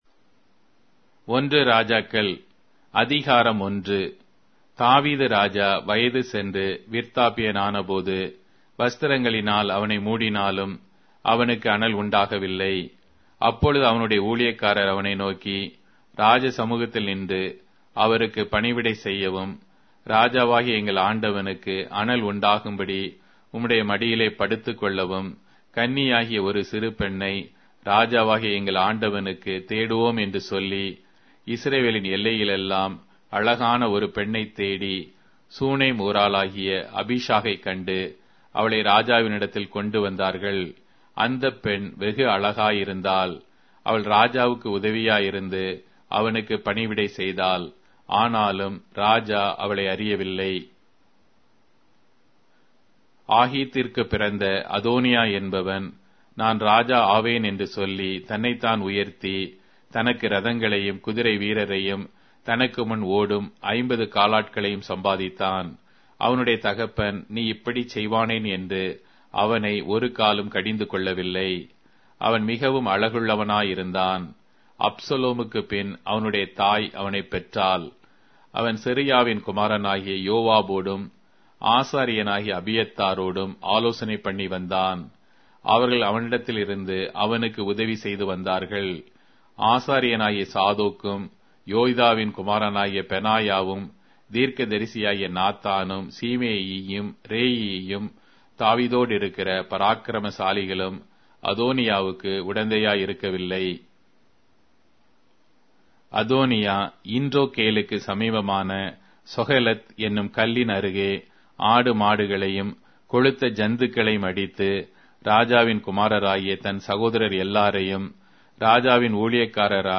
Tamil Audio Bible - 1-Kings 9 in Tev bible version